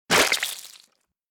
splat.mp3